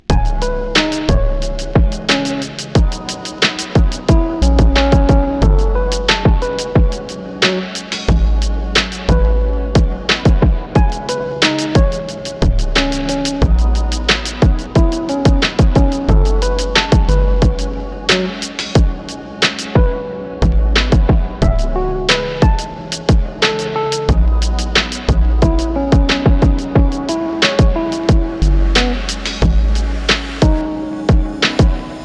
Chill
Bumpy Thump
Rap Club
Db Minor
Classic EP